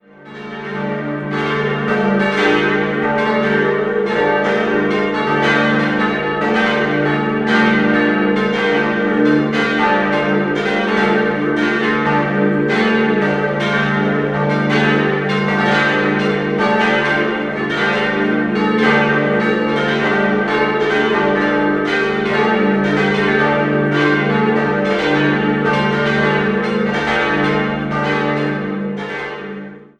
5-stimmiges Geläute: d'-f'-g'-a'-c'' Die Glocken 5 und 2 wurden 1869 von Lothar Spannagl in Regensburg gegossen, die Glocken 4 und 3 von Karl Hamm in Regensburg und die große im Jahr 1966 von Georg Hofweber in Regensburg.